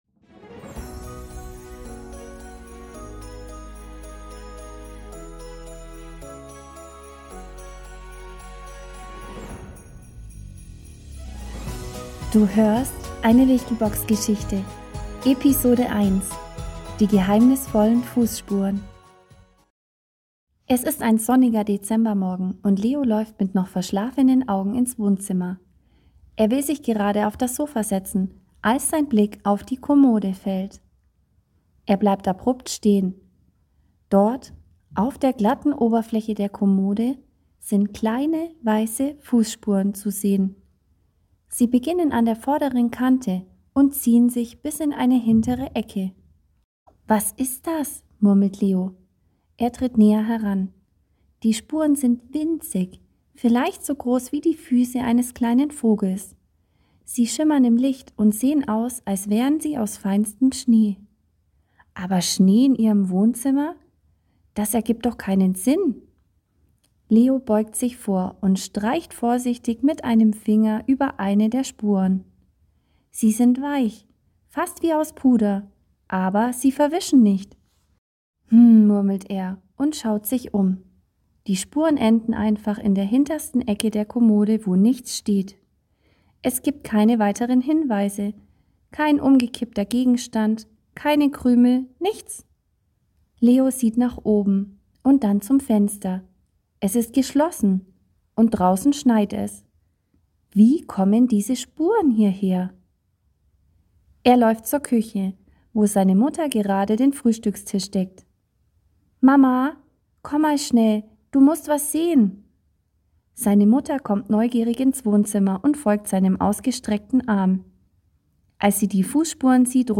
Dazu habe ich euch ein schönes kurzes Hörspiel herausgesucht von der Seite Wichtelbox.